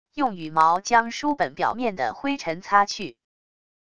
用羽毛将书本表面的灰尘擦去wav音频